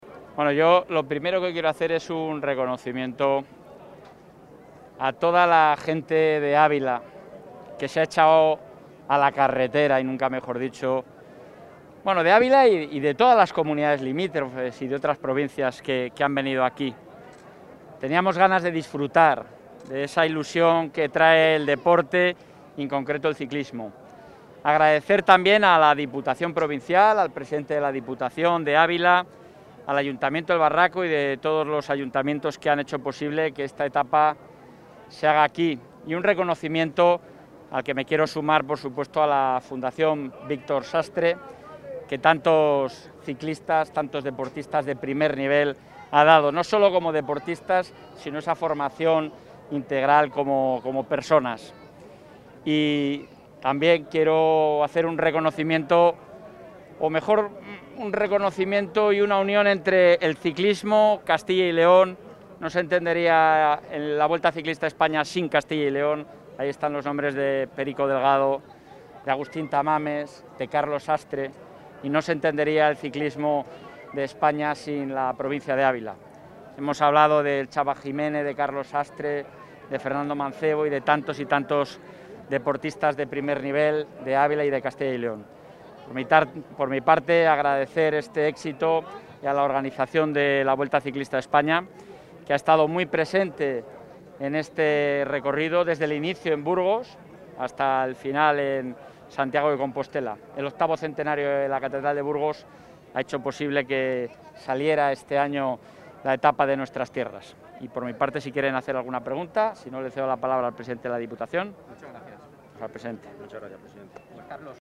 Intervención del presidente.
El presidente de la Junta de Castilla y León, Alfonso Fernández Mañueco, ha asistido a la 15 etapa de La Vuelta, que discurre entre Navalmoral de la Mata (Cáceres) y El Barraco (Ávila).